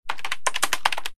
keyboard6.ogg